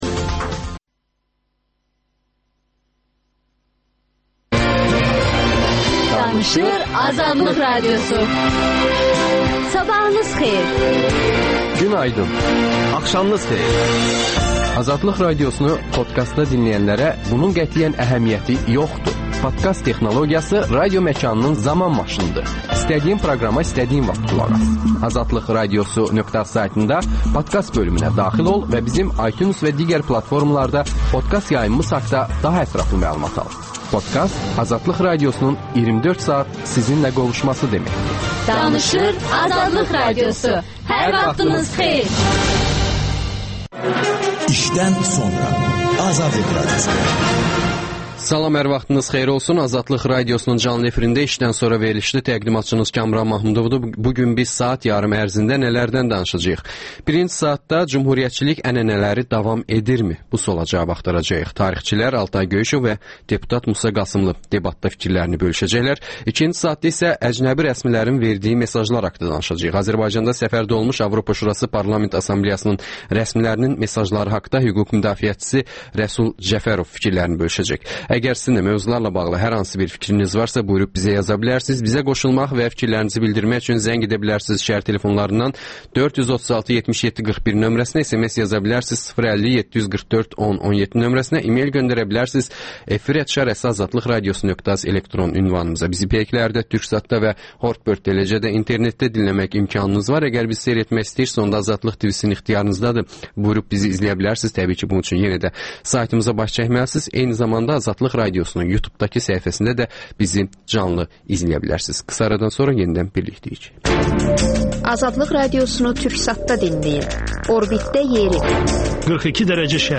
Tarixçilər